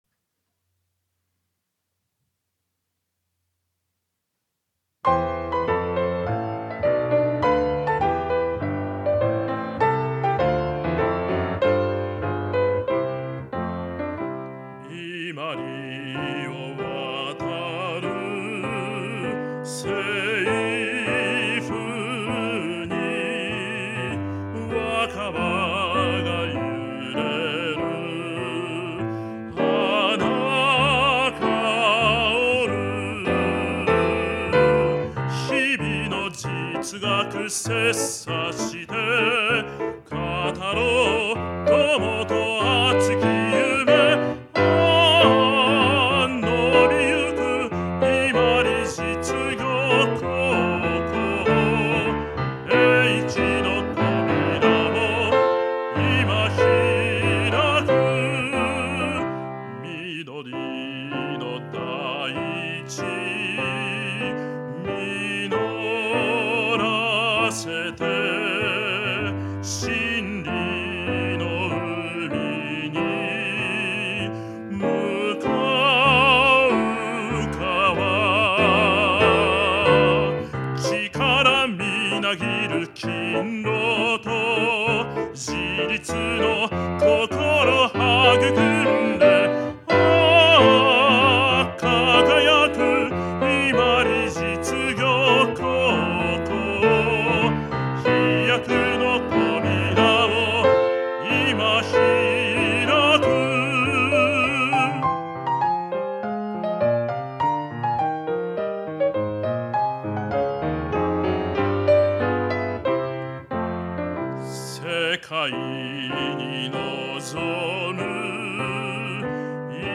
校訓/校歌/校章
伊万里実業高校校歌（歌あり.mp3